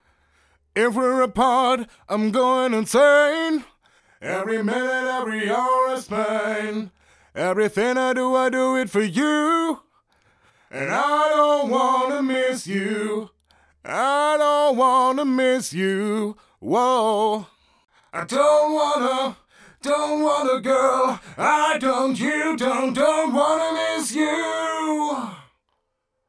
Besonders geeignet für das Anfetten von Vocals sind dabei beispielsweise der Chorus und der Harmonizer.
Harmonizer
Einer anderen Darstellung zufolge splittet ein Harmonizer das Originalsignal in zwei unterschiedliche Pfade auf, die unterschiedlich verstimmt und hart links bzw. rechts gepannt werden.
modulationseffekte_zur_stereoverbreiterung_tutorial__07_harmonizer.mp3